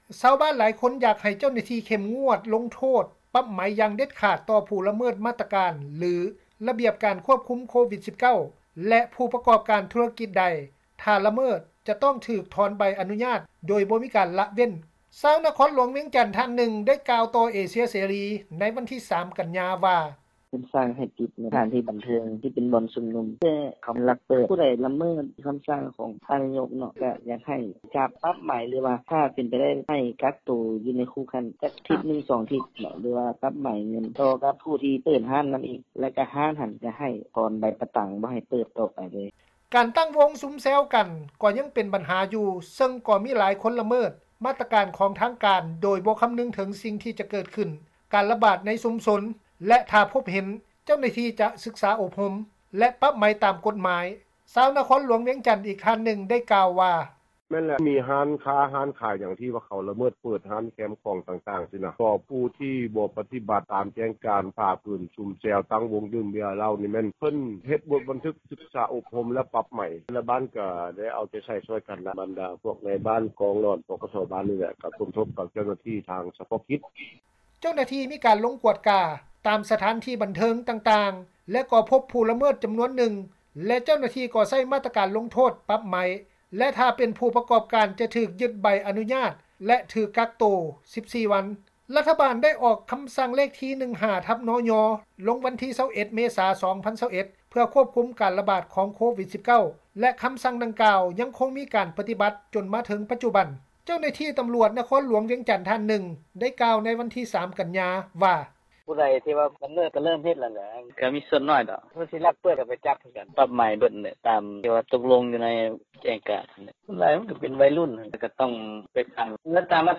ຊາວນະຄອນຫຼວງ ວຽງຈັນ ທ່ານນຶ່ງ ໄດ້ກ່າວຕໍ່ ວິທຍຸເອເຊັຽເສຣີ ໃນວັນທີ 3 ກັນຍາ ວ່າ:
ຊາວນະຄອນຫລວງວຽງຈັນ ອີກທ່ານນຶ່ງ ໄດ້ກ່າວວ່າ:
ເຈົ້າໜ້າທີ່ ຕຳຣວດ ນະຄອນຫຼວງ ວຽງຈັນ ທ່ານນຶ່ງ ໄດ້ກ່າວວ່າ ໃນວັນທີ 3 ກັນຍາ ວ່າ: